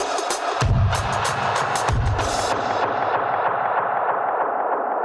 Index of /musicradar/dub-designer-samples/95bpm/Beats
DD_BeatFXB_95-01.wav